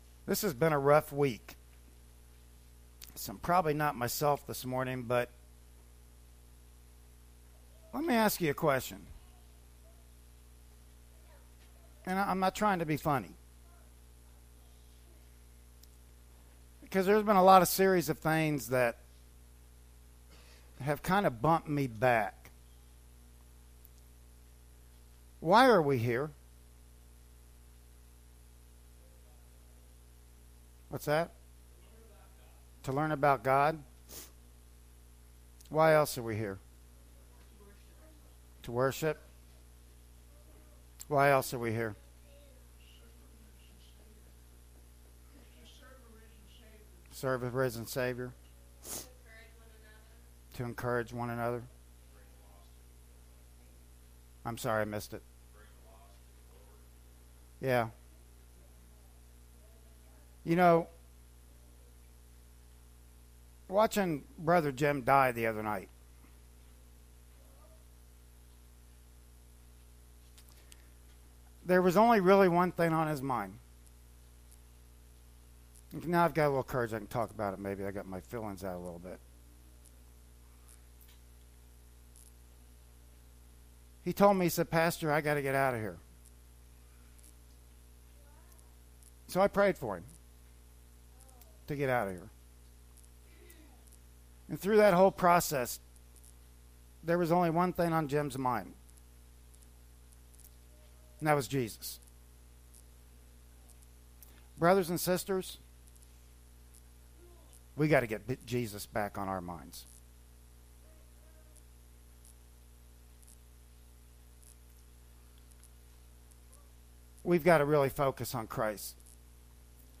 "Micah 6:1-8" Service Type: Sunday Morning Worship Service Bible Text